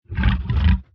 25w18a / assets / minecraft / sounds / mob / zoglin / idle5.ogg